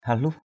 /ha-luh/